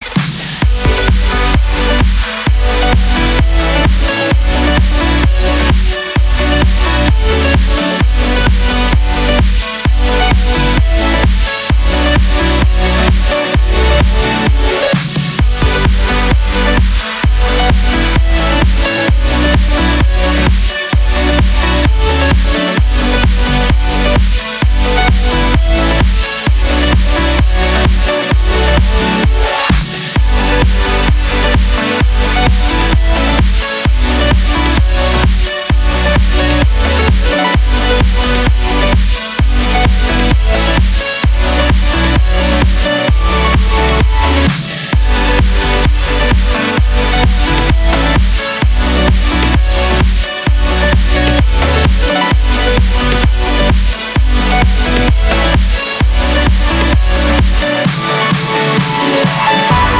• Качество: 256, Stereo
громкие
красивые
без слов
progressive trance
Trance